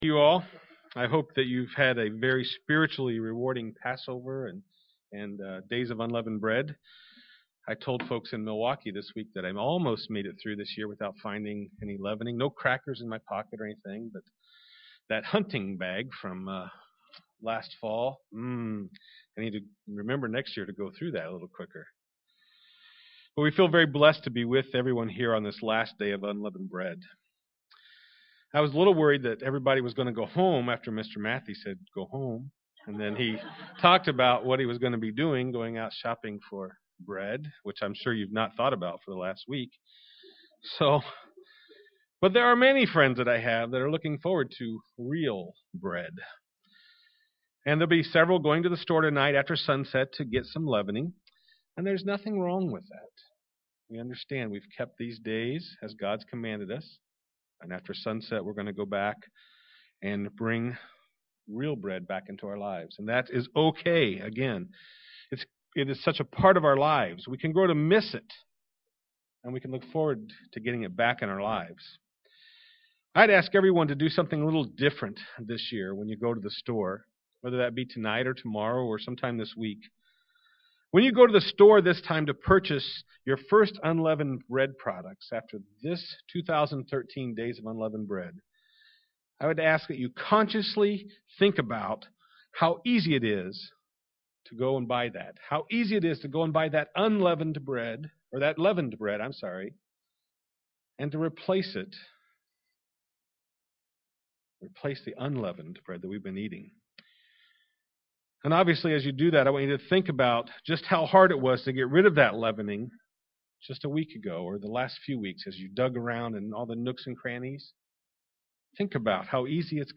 We must never allow our opinions or talents to come between us and the word of God. This message was given on the Last Day of Unleavened Bread.
UCG Sermon Studying the bible?